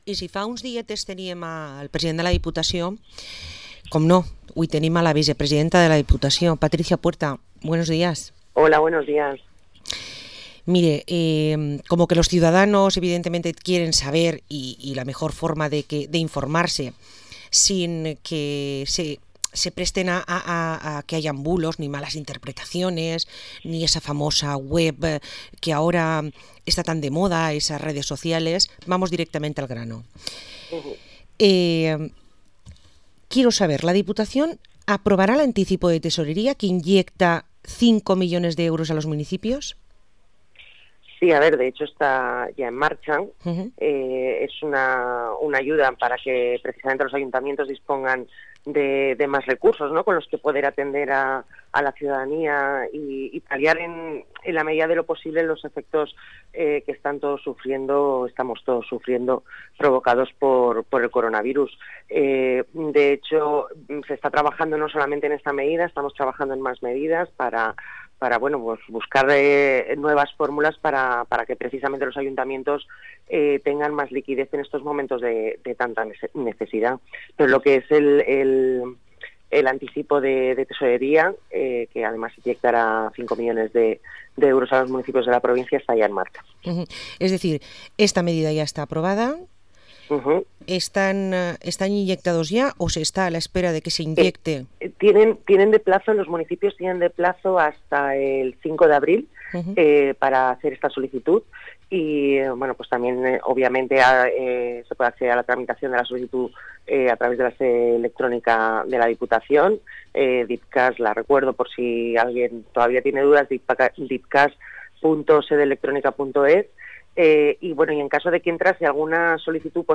Entrevista a Patricia Puerta, vicepresidenta de la Diputación de Castelló